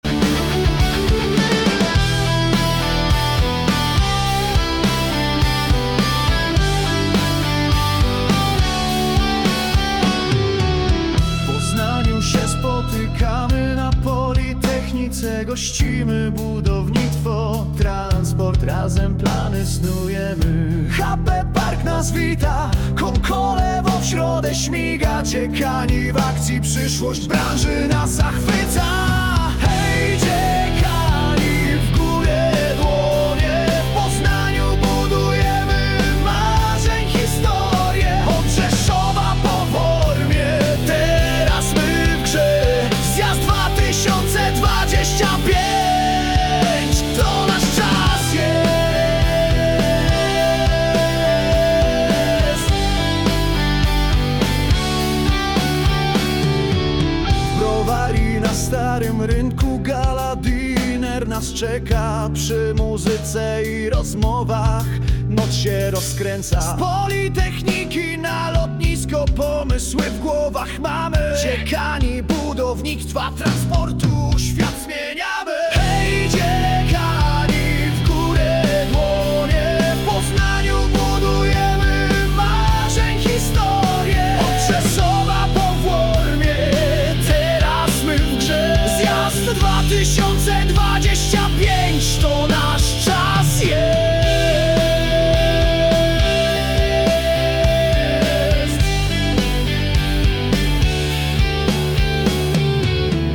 Hymn dziekanów.mp3